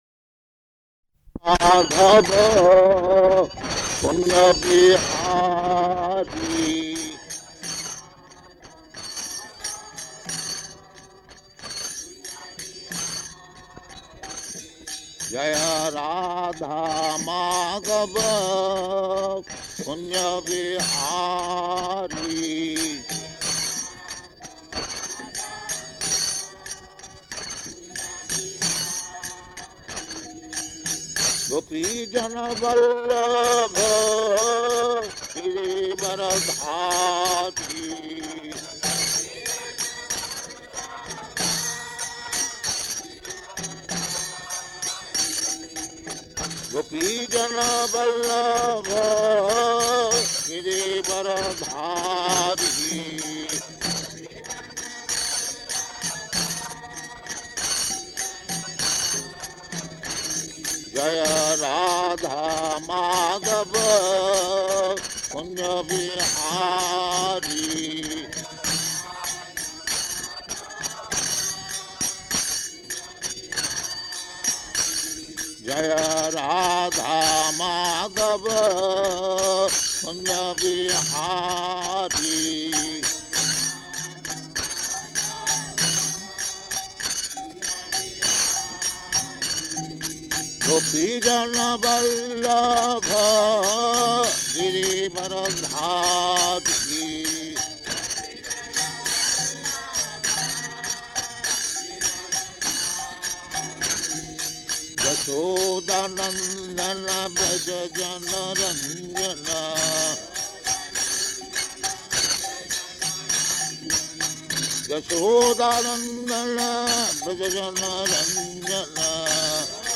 Location: Hyderabad
740423SB.HYD.mp3 Prabhupāda: [Chants jaya rādhā-mādhava ]
[Prabhupāda and devotees repeat]